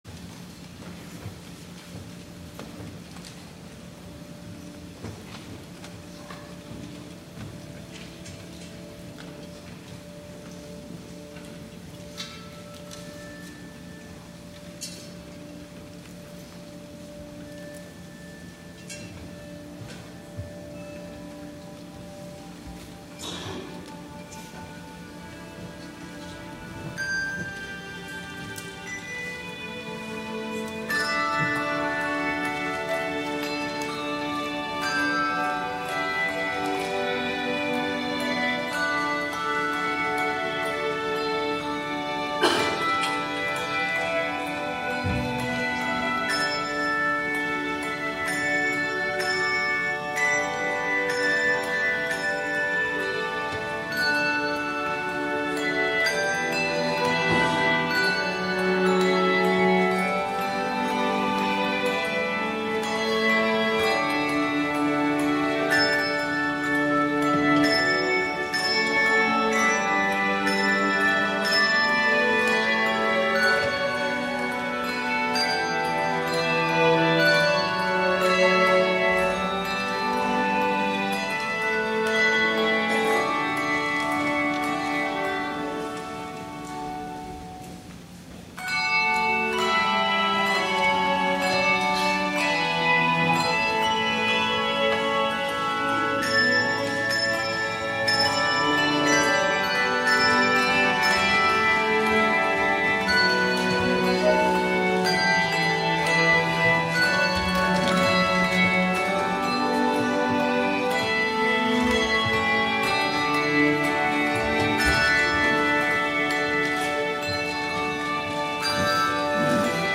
THE OFFERTORY
Handbell Choir
cello
viola
violin